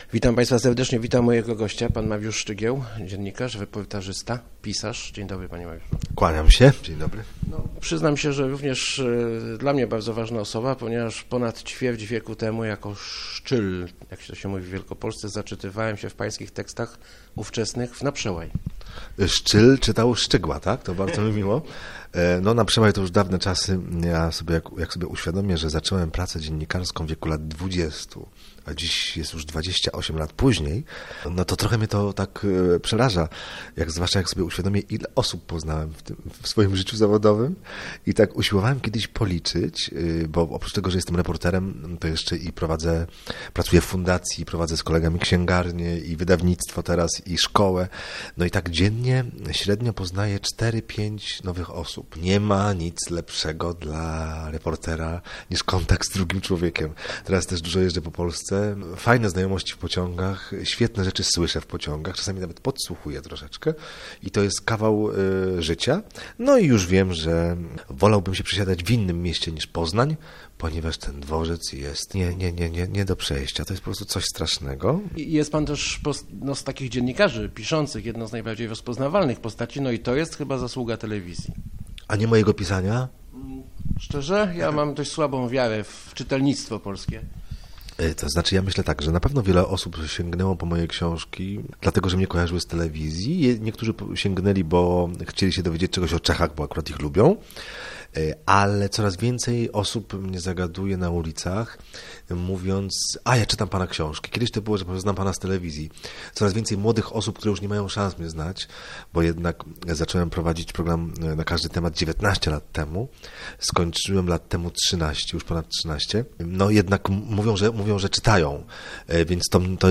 Gdybym nie odszedł z telewizji, byłbym dziś jak blond Krzysztof Ibisz - mówił w Rozmowach Elki Mariusz Szczygieł, dziennikarz i pisarz. Jak podkreślił, pisanie reportaży daje mu większą swobodę.